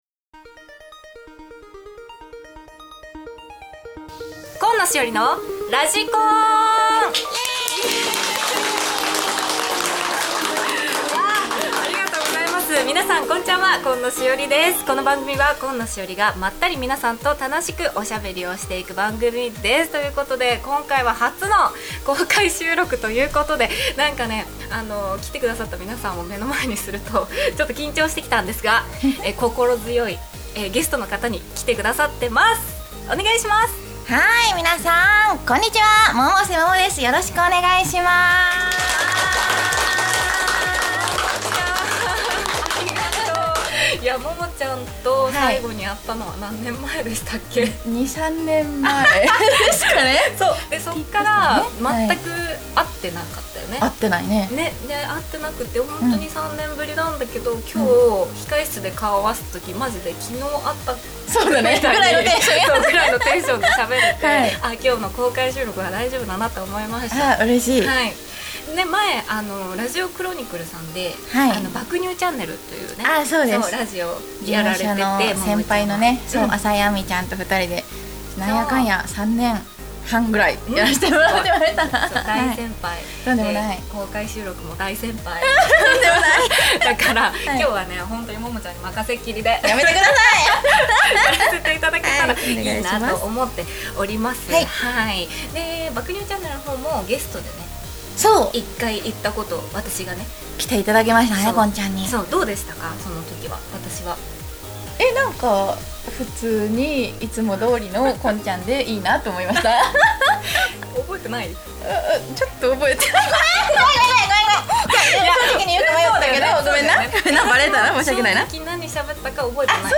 番組初の公開収録です！